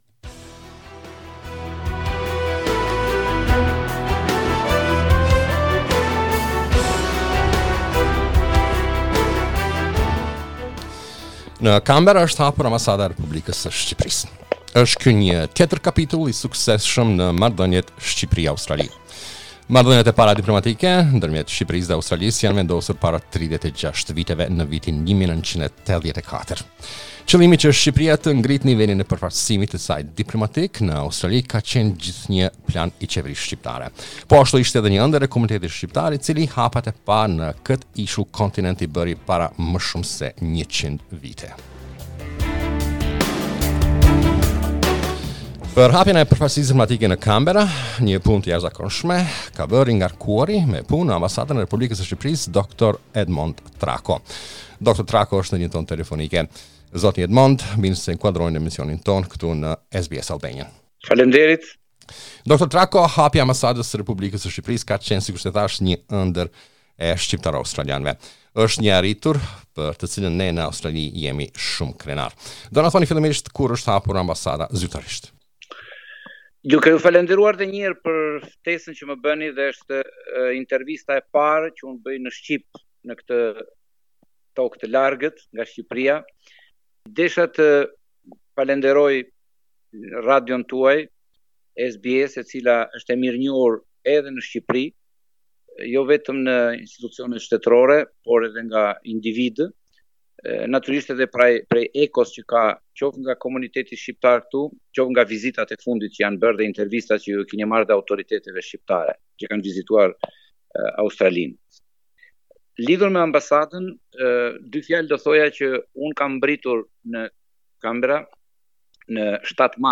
Ne biseduam me te rreth procesit te hapjes se ambasades, sfidave dhe planeve te tij per te ardhmen.